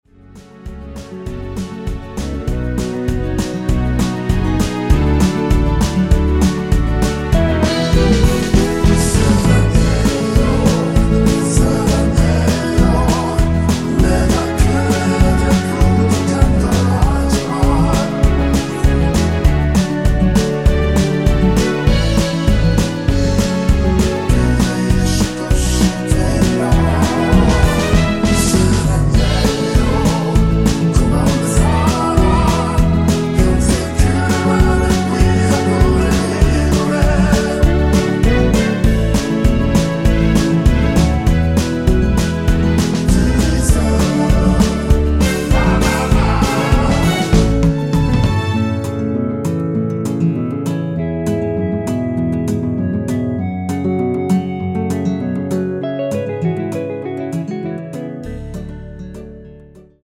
전주가 길어서 8마디로 편곡 하였으며
원키에서(-3)내린 (1절+후렴)으로 진행되는 멜로디와 코러스 포함된 MR입니다.
앞부분30초, 뒷부분30초씩 편집해서 올려 드리고 있습니다.
중간에 음이 끈어지고 다시 나오는 이유는